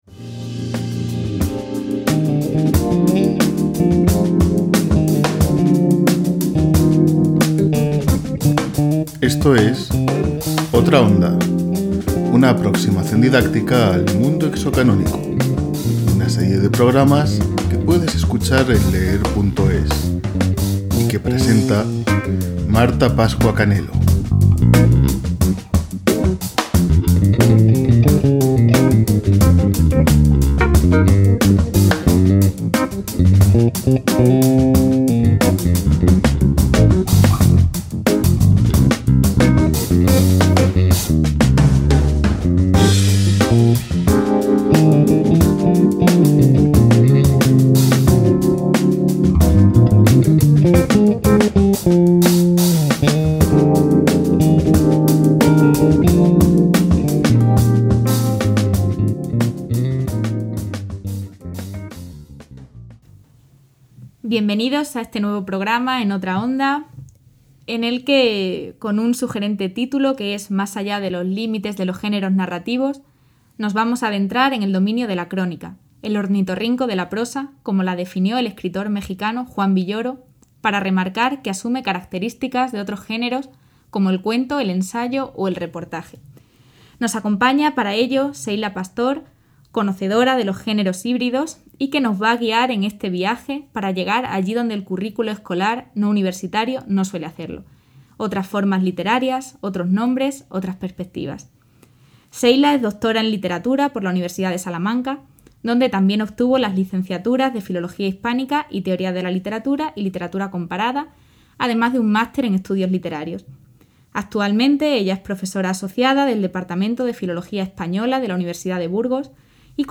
Esta serie de pódcasts ofrece a los docentes y estudiantes interesados siete capítulos en los que varios expertos de la Universidad de Salamanca son entrevistados para hablar de las ventajas y posibilidades educativas de introducir en el aula objetos culturales ajenos al canon cultural dominante. Se exponen cuestiones relevantes, útiles e interesantes para la sociedad actual que han quedado tradicionalmente fuera del canon escolar: la ciencia ficción, los videojuegos, el rock progresivo o la realidad de las personas trans son algunas de las materias abordadas a lo largo de estos programas.